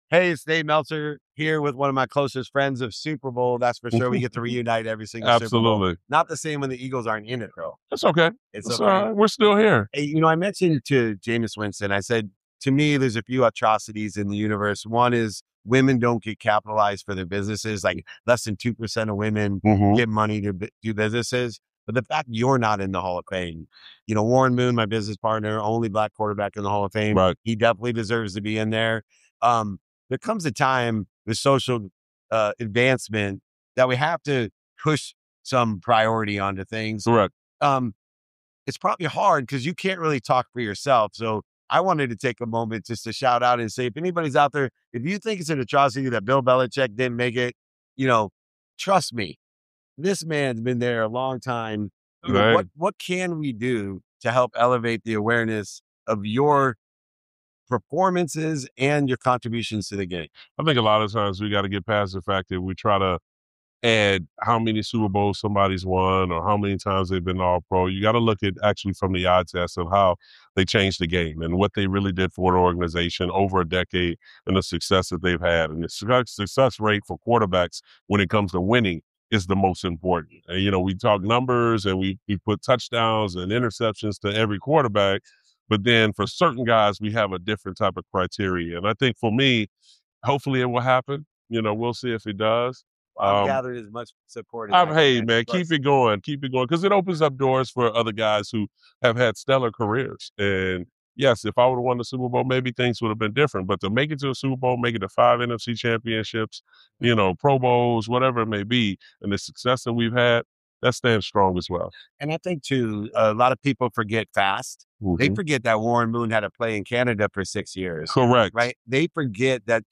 In today’s episode, I sit down with former NFL quarterback Donovan McNabb, a 13-season veteran best known for leading the Philadelphia Eagles to five NFC Championship appearances and a Super Bowl. We talk about legacy, the Hall of Fame conversation, and how quarterbacks are evaluated beyond rings and stats.